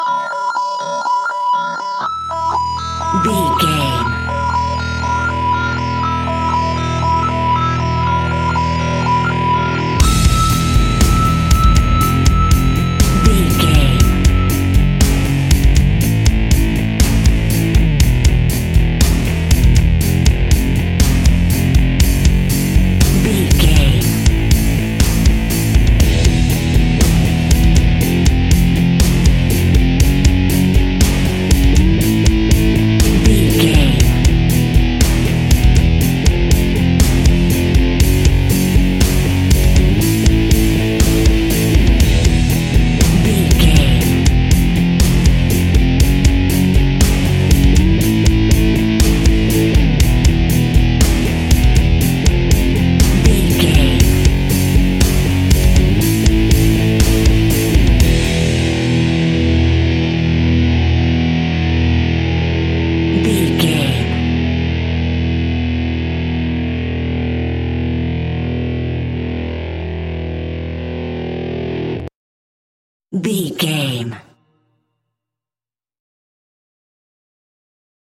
Ionian/Major
hard rock
heavy metal
instrumentals
Rock Bass
heavy drums
distorted guitars
hammond organ